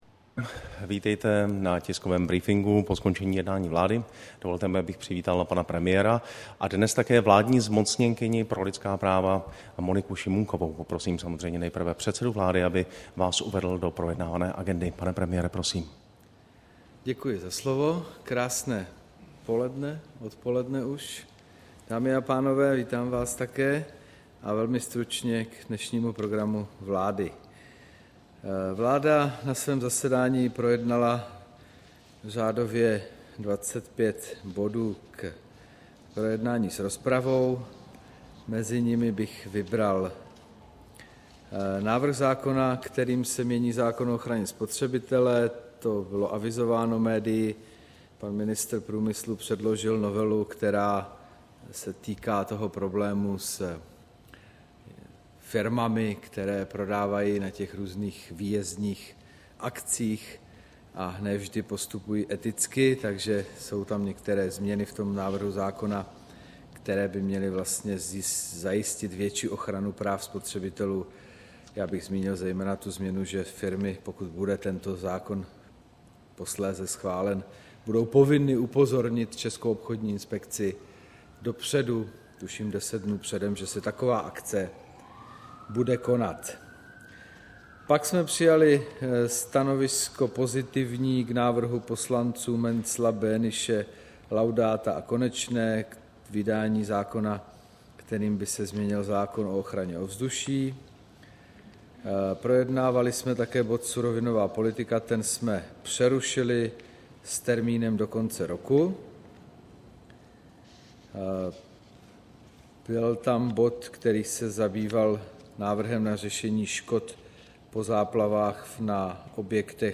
Tisková konference po jednání vlády, 14. srpna 2013